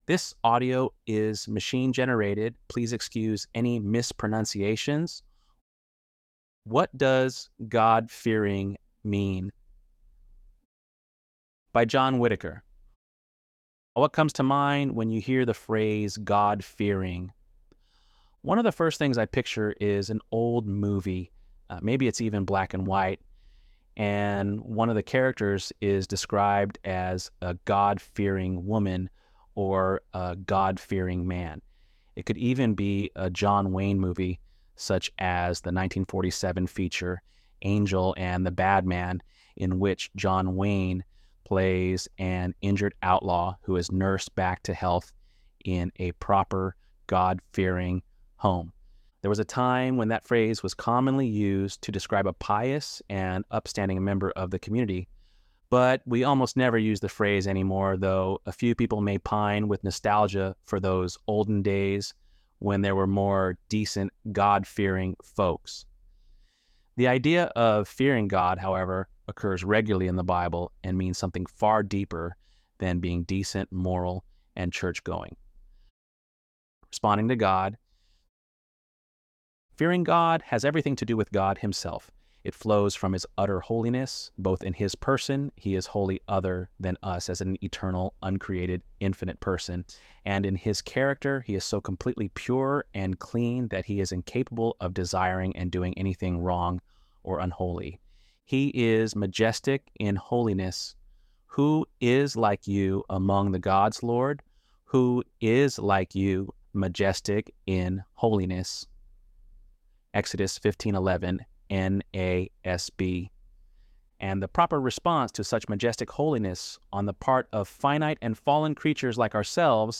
ElevenLabs_1-2.mp3